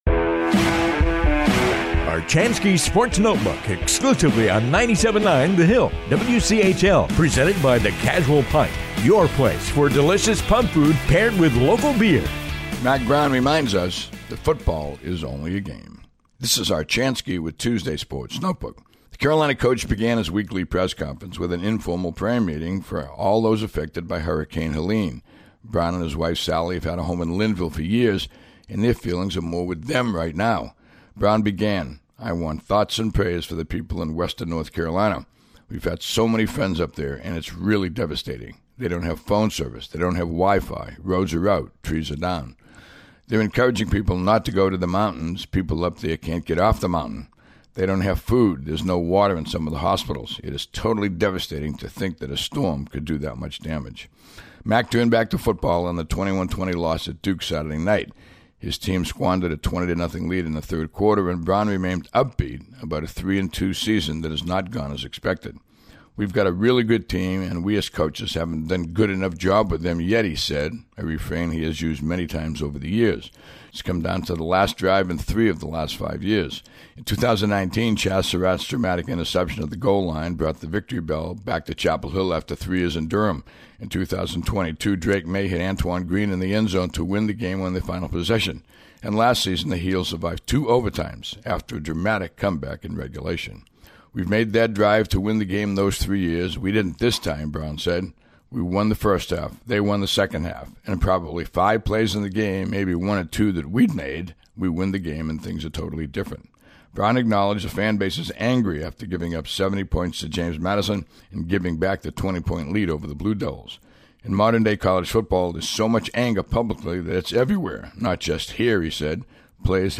The Carolina coach began his weekly press conference with an informal prayer meeting for all those affected by Hurricane Helene.